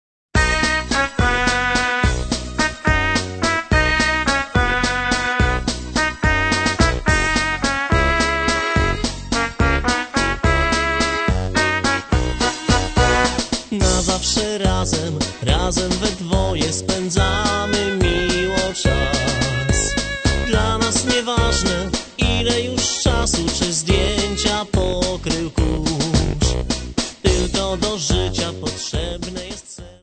3 CD set of Polish Folk Songs.